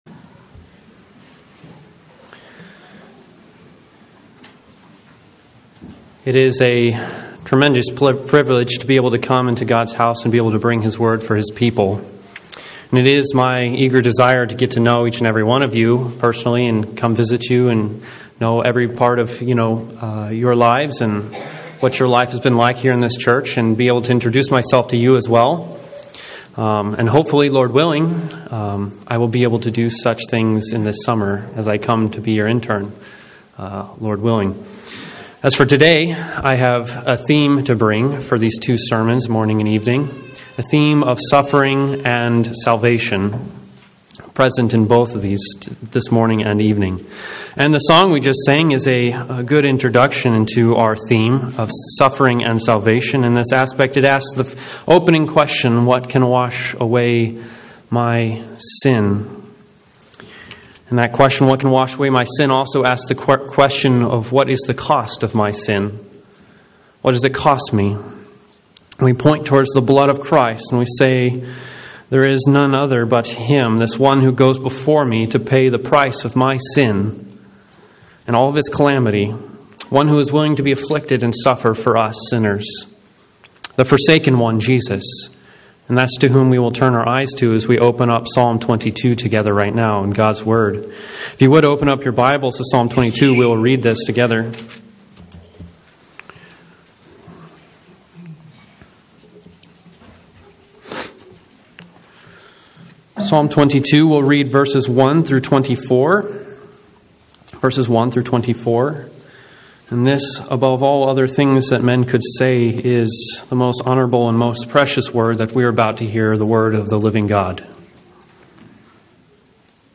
Occasional Sermons